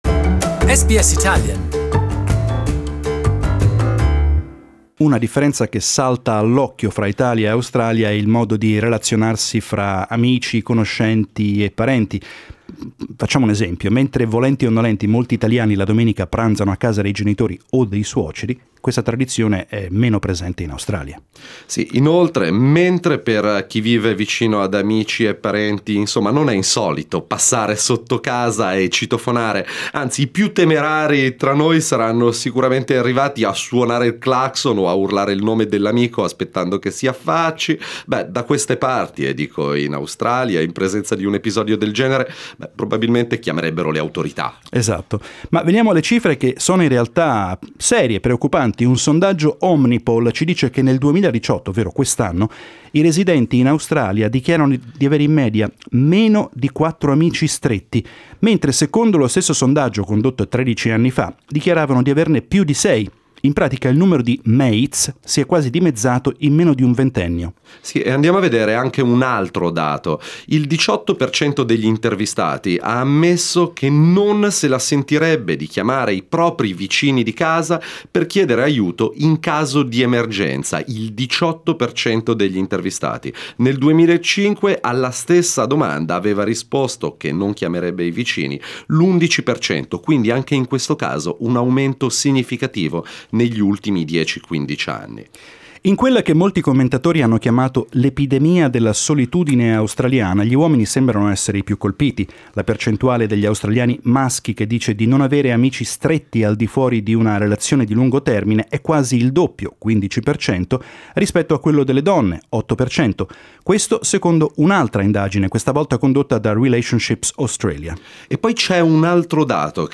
Data show an increase in loneliness among adult Australians. Our talkback.